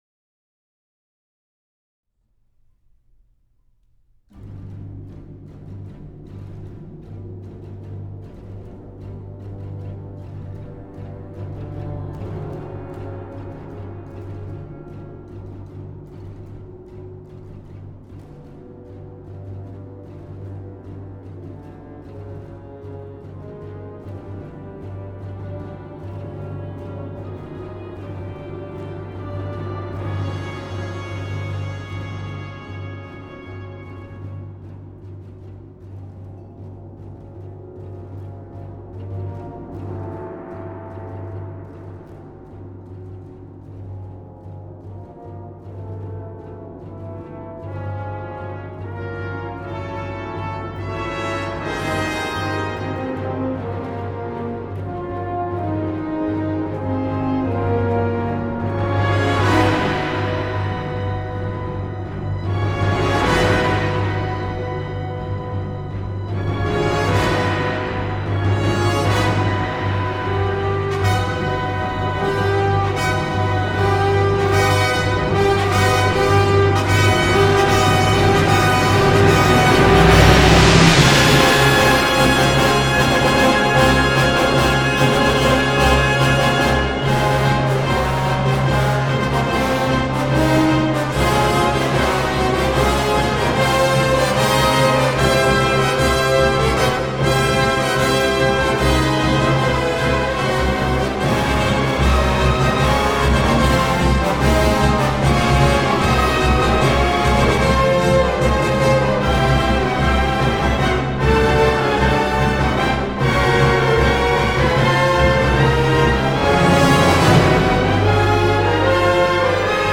This is the most terrifying music I own.
” is the second movement of the orchestral suite